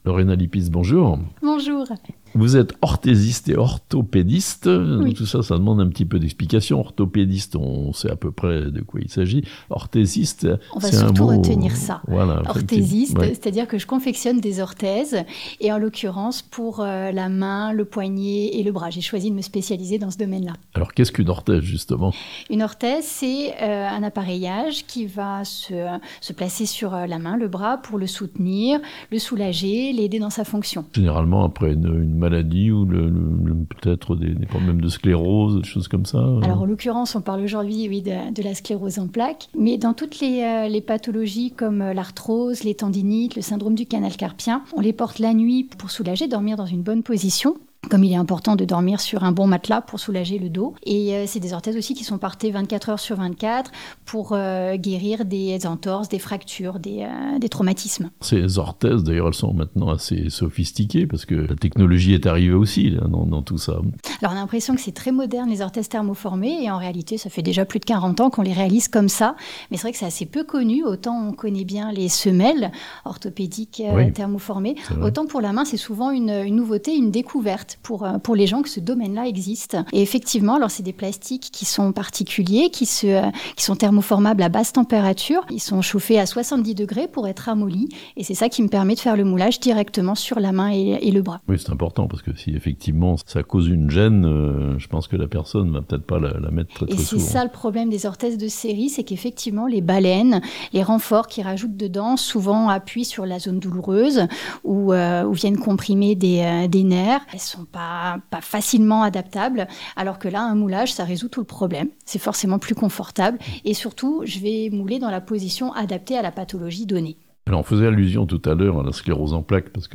L' Orthésiste est un professionnel de santé qui soigne les pathologies osseuses, articulaires ou musculaires. Interview d'une orthésiste chablaisienne.